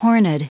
Prononciation du mot horned en anglais (fichier audio)
Prononciation du mot : horned